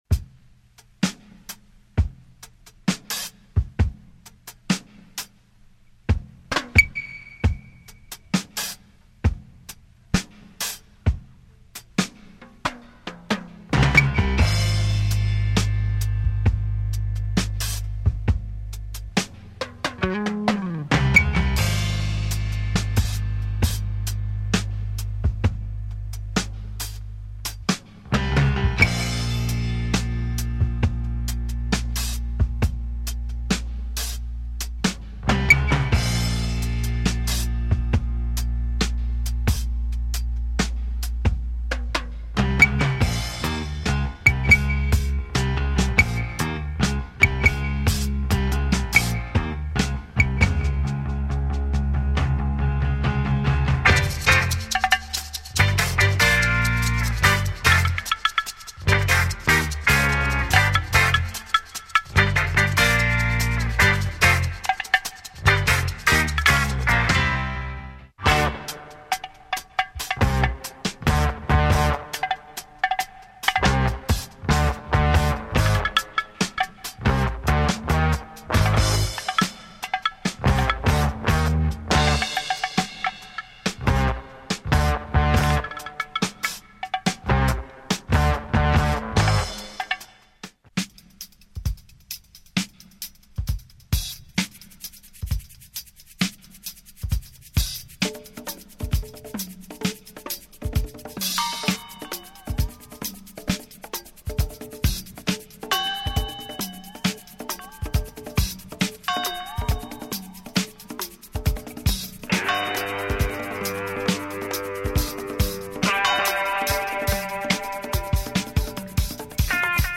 Excellent progressive jazz & groove album from Canada.